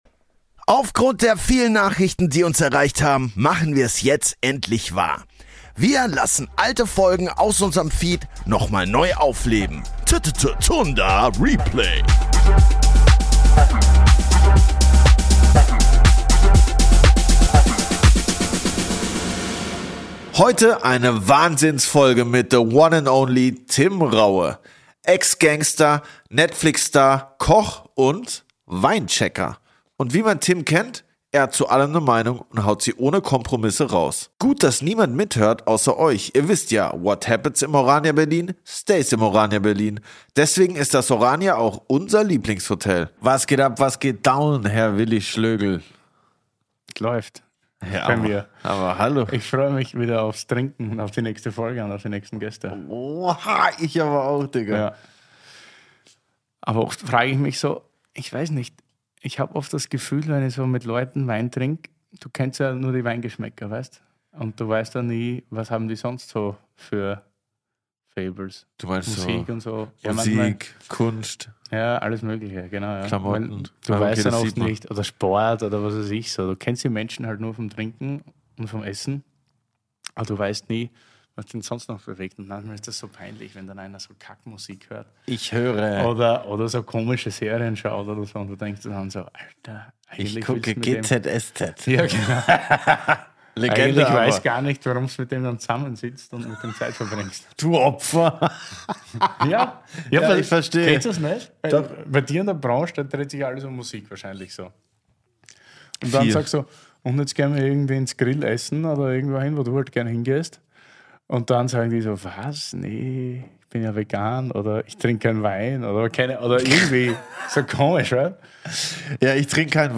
Tim persönlich, 90 Minuten lang, live, raw und ungeschnitten – das ist nochmal ein ganz anderer Schnack.